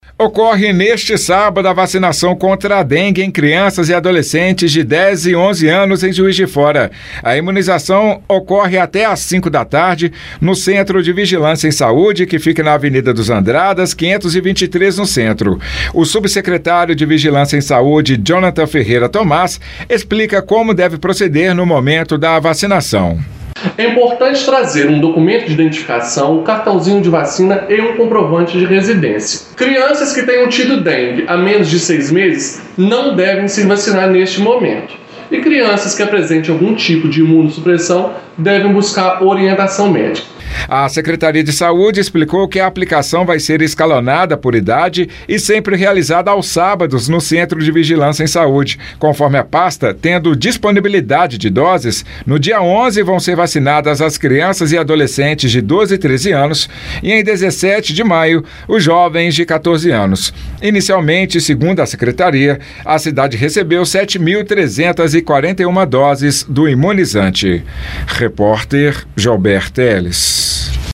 O subsecretário de Vigilância em Saúde, Jonathan Ferreira Thomaz, explicou os documentos necessários e se a criança que teve a doença recentemente deve se vacinar neste momento.